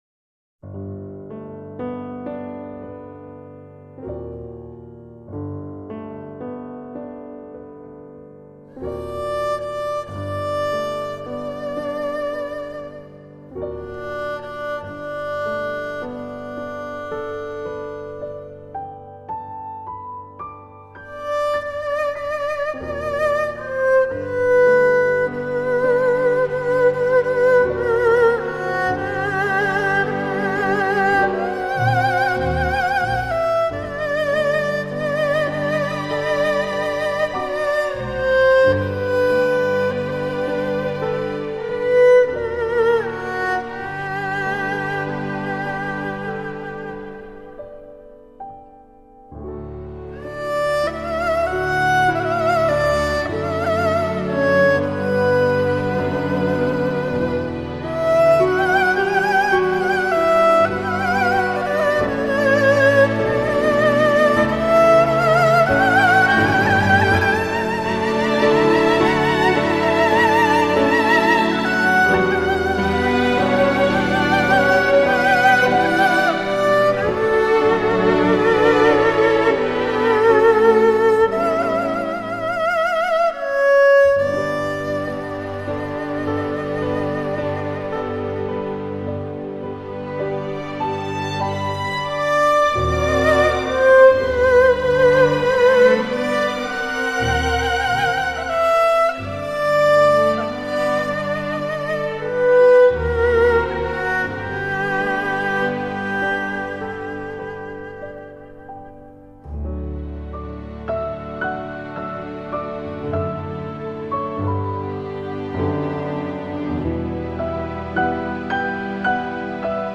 二胡の音色は人の歌声に近いといわれる。